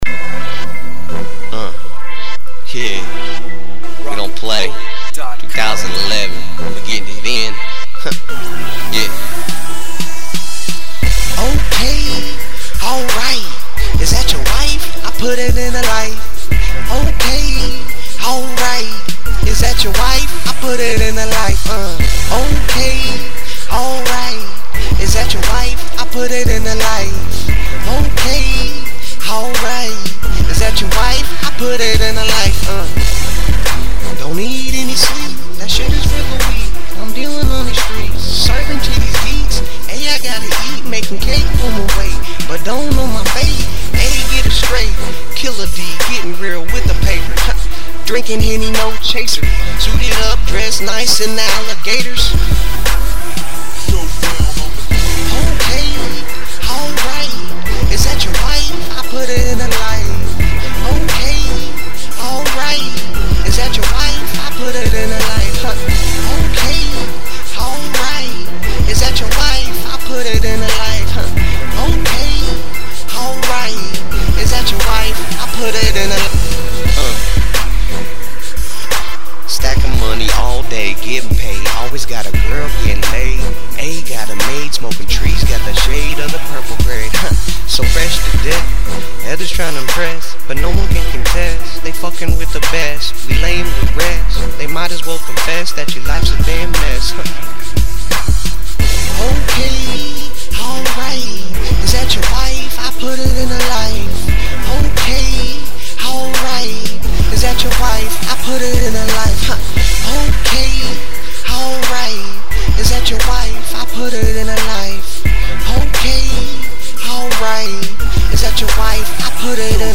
New to recording.
Rough copy...